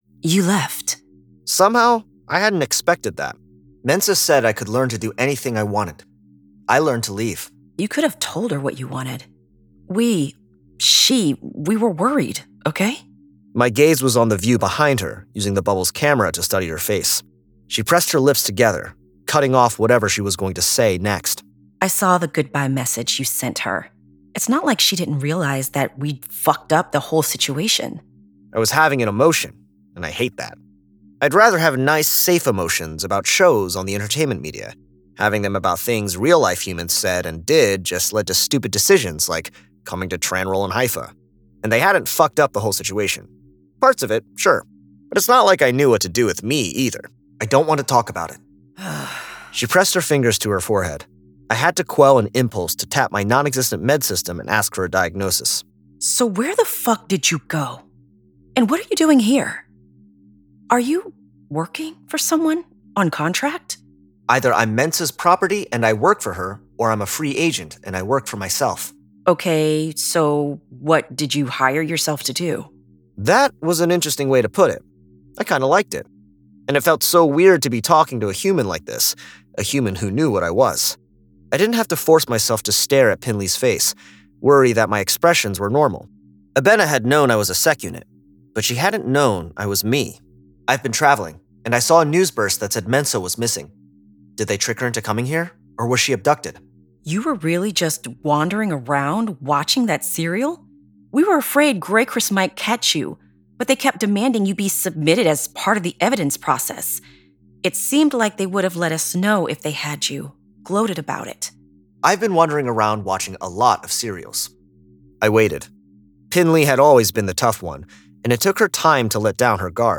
Full Cast. Cinematic Music. Sound Effects.
[Dramatized Adaptation]
Adapted from the novel and produced with a full cast of actors, immersive sound effects and cinematic music!